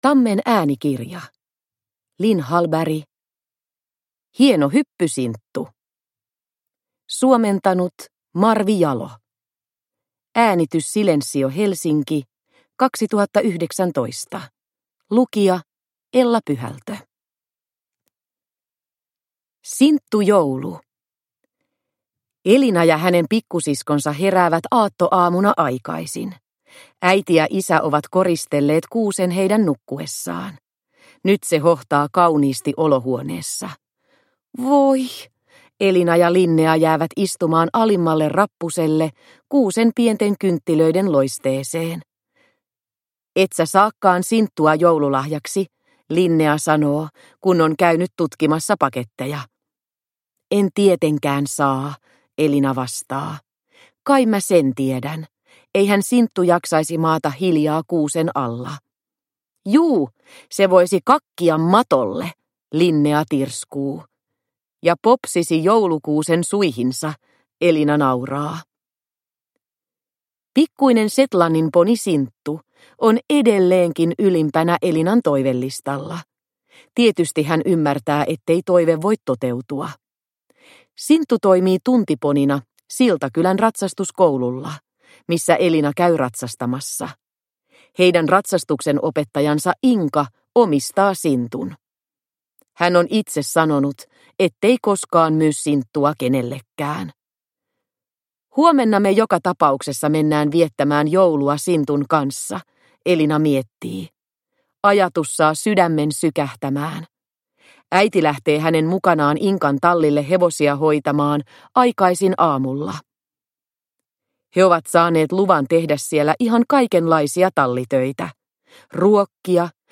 Hieno hyppy, Sinttu – Ljudbok – Laddas ner